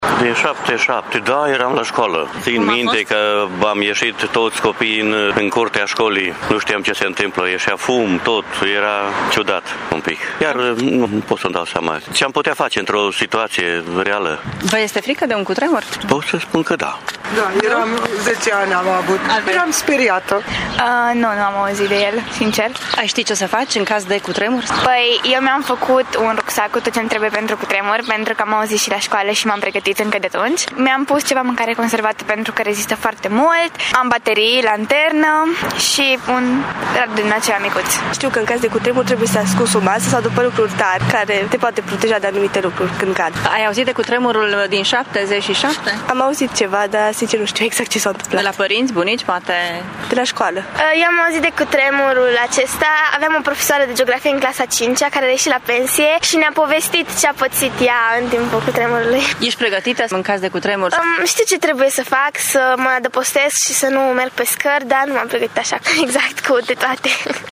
Seismul s-a simțit destul de tare și în Târgu-Mureș, își amintesc unii localnici. Cei mai tineri știu mai puține despre acest cutremur iar cei mai prevăzători și-au pregătit un rucsac în caz de cutremur: